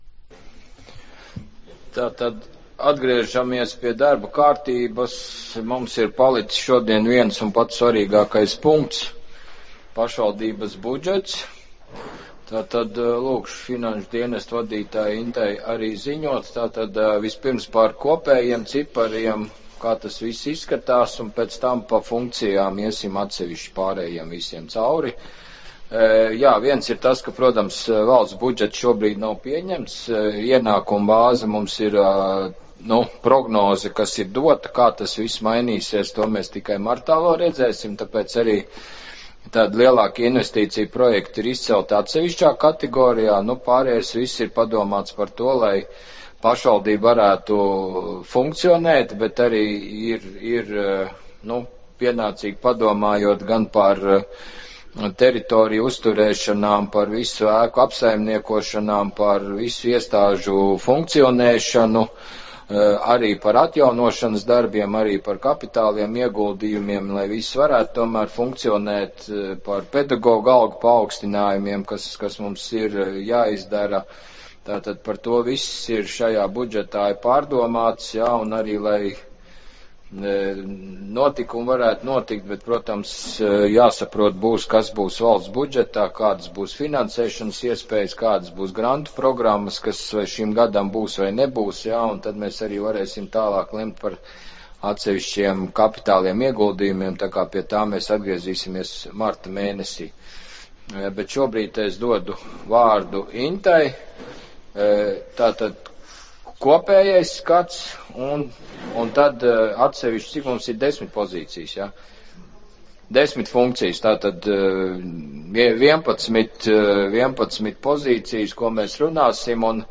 Valmieras novada pašvaldības domes pastāvīgo komiteju kopīgā sēdes notiks ceturtdien, 2023.gada 26.janvārī plkst.8.30., Kocēnu Kultūras namā, Alejas ielā 3, Kocēnos, Kocēnu pagastā, Valmieras novadā.